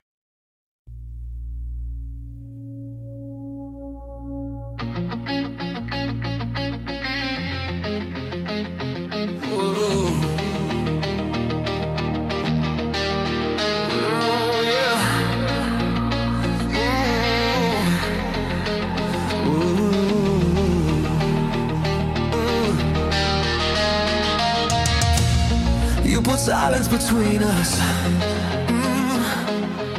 Fusión de estilos que amplían el universo sonoro.